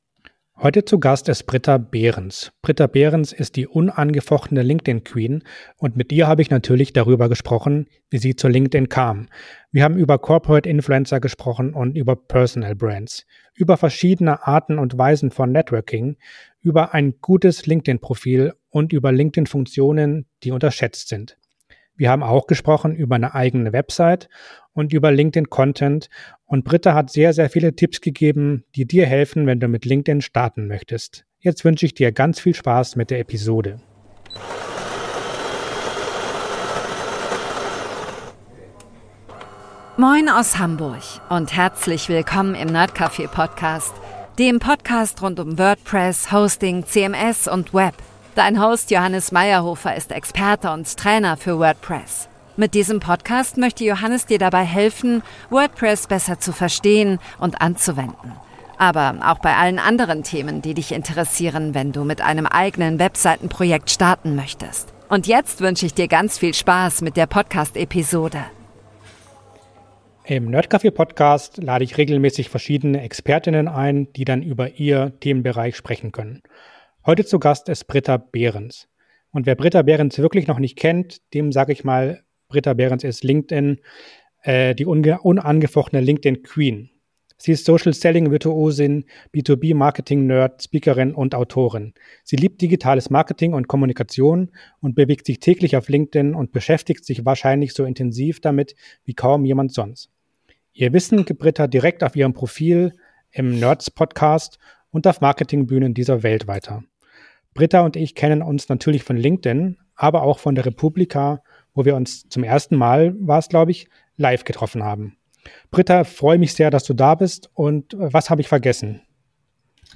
Der nerdcafe Podcast steht für ein offenes, vielfältiges und zugängliches Internet – verständlich erklärt, entspannt im Ton, aber mit Tiefgang.